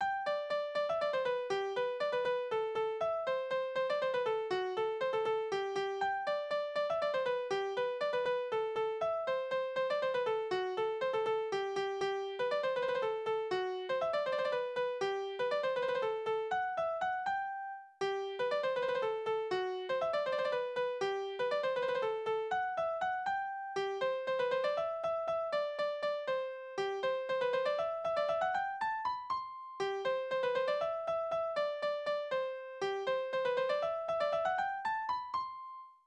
Schelmenlieder: Ich und mein Weib
Tonart: G-Dur
Taktart: 3/8
Tonumfang: verminderte Duodezime
Besetzung: vokal
Anmerkung: die zweite Hälfte besitzt keinen Text